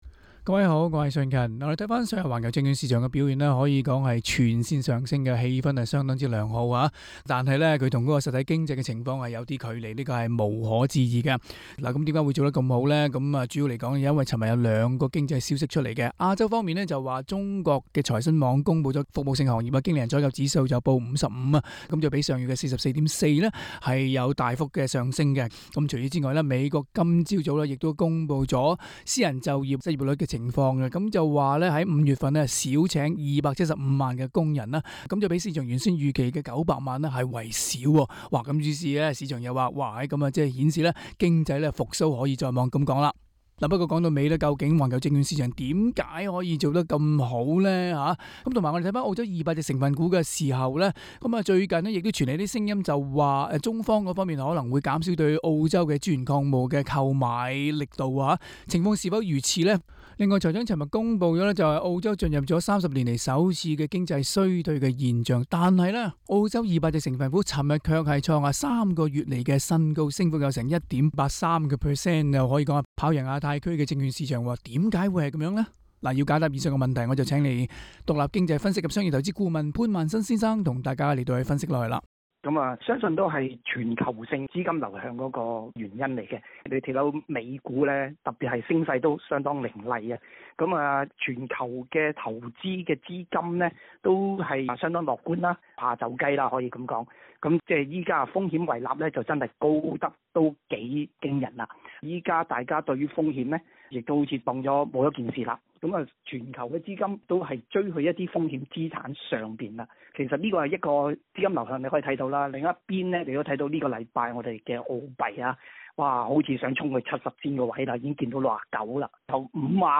詳情請大家收聽這個網綕的訪問内容。